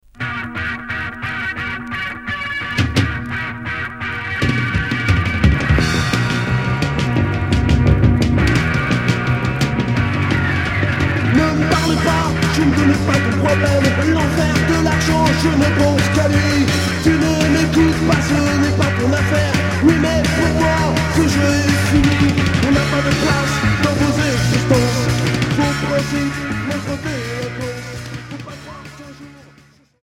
Punk rock Premier 45t retour à l'accueil